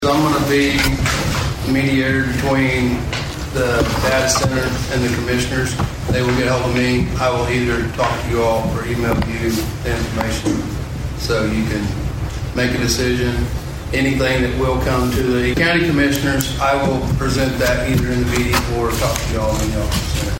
The Board of Osage County Commissioners met at the fairgrounds for a regularly scheduled meeting at the fairgrounds on Monday morning.
Assessor Ed Quinton Jr. talks about how he will be of assistance when the data center